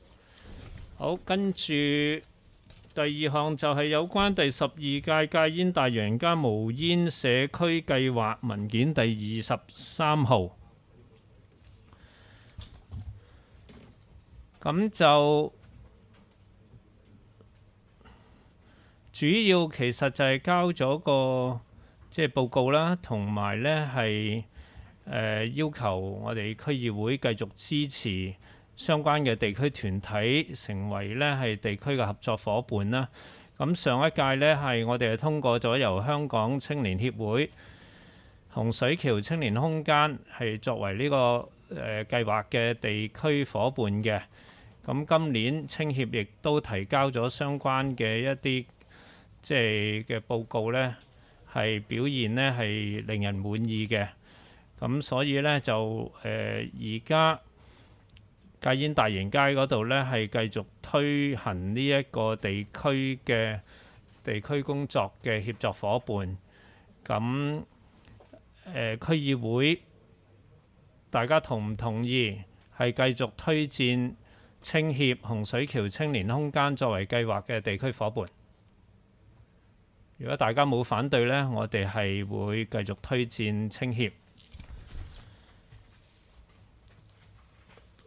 区议会大会的录音记录
地点: 元朗桥乐坊2号元朗政府合署十三楼会议厅